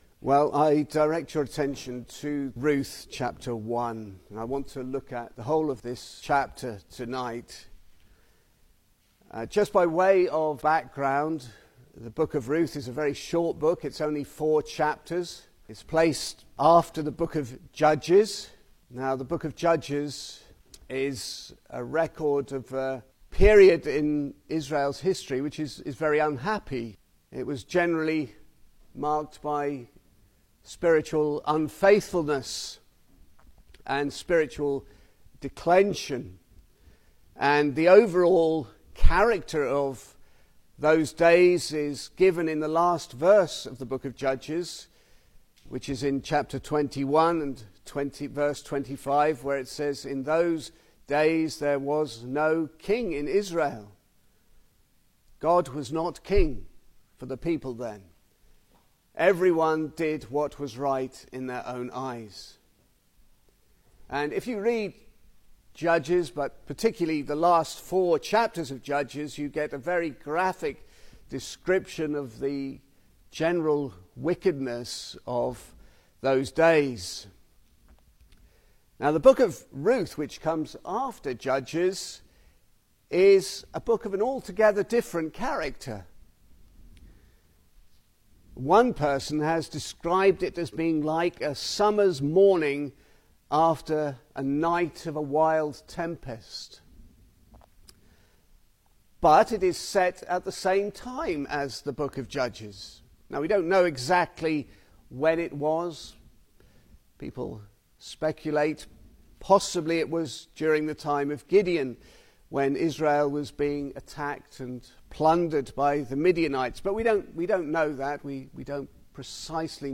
2026 Service Type: Sunday Evening Speaker
Single Sermons Book: Ruth Scripture: Ruth 1 https